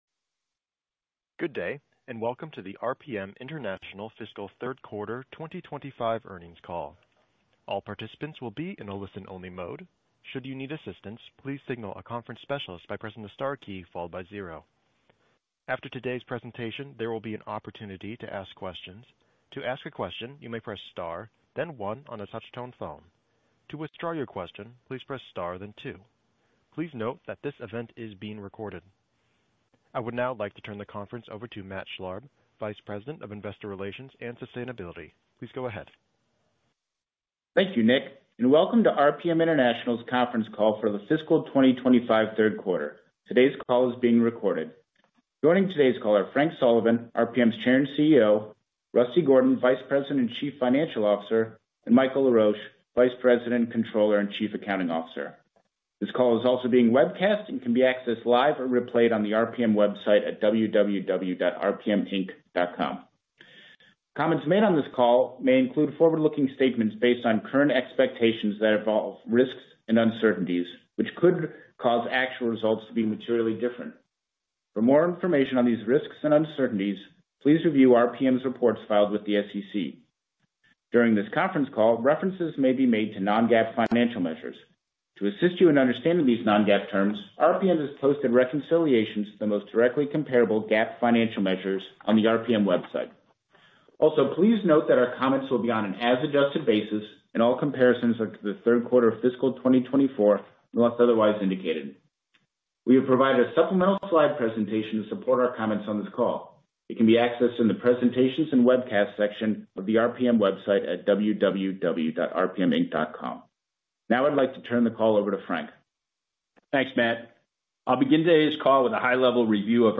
rpm-q3-25-earnings-call-audio.mp3